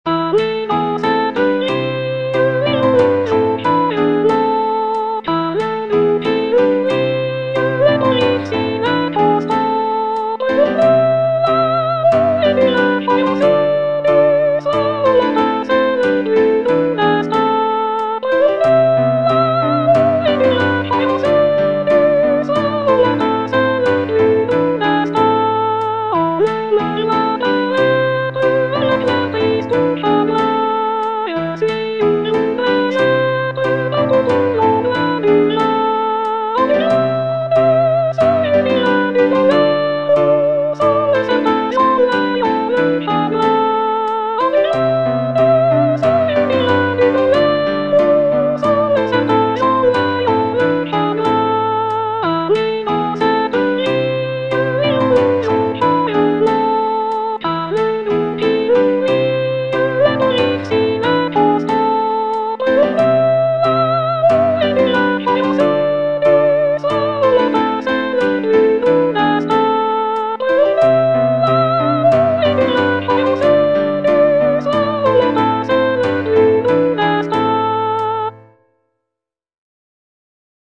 Soprano (Voice with metronome)
traditional French folk song